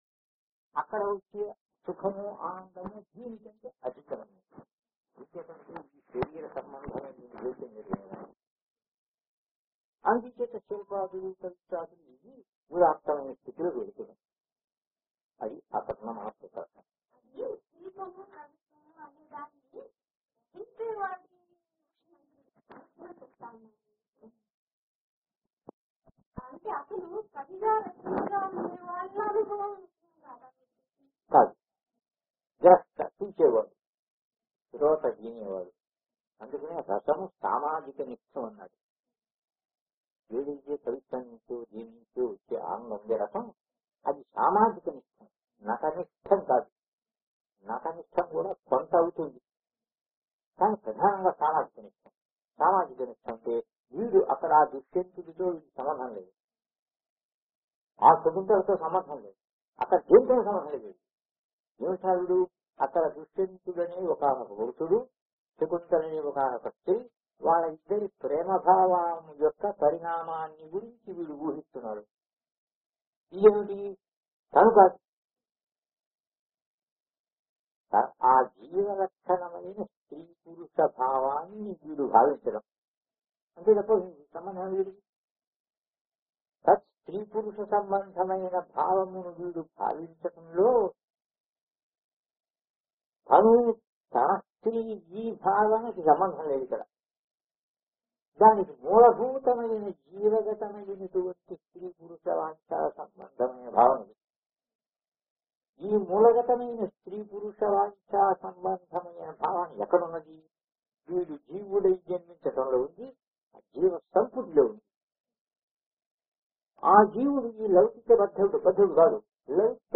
[బాగా పాత రికార్డింగ్ కావడంతో మేము వీలైనంతగా శుభ్రం చేసినా అక్కడక్కడా ఆడియో అంత స్పష్టంగా లేదు.